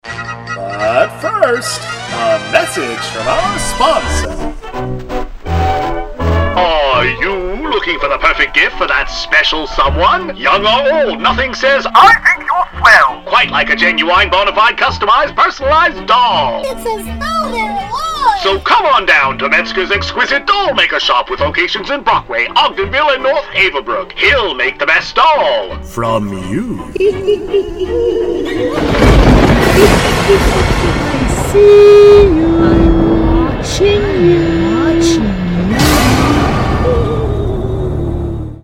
Each world intricately recreates familiar scenes laid out exactly as they once were, brought back to life through theatrical lighting transitions and their own iconic background music tracks, each leading with a thematic mini-preshow introductory announcement.